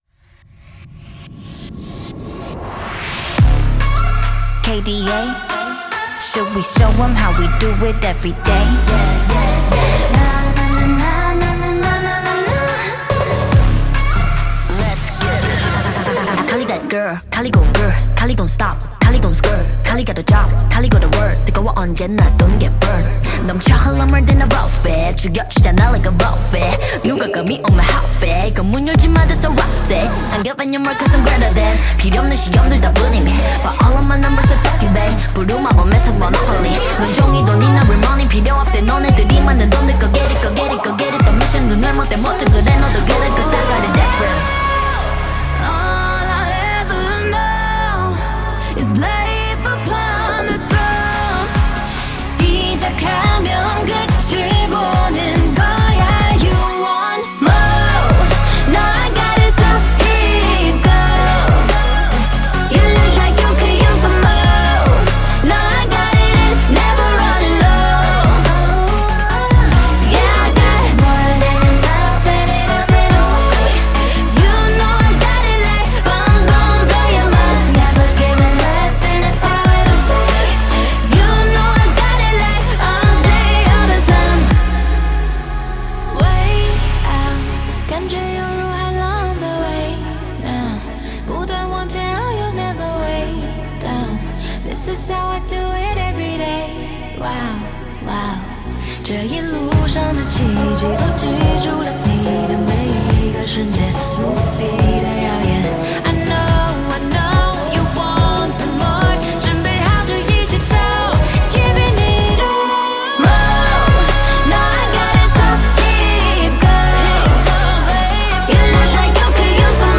BPM142
MP3 QualityMusic Cut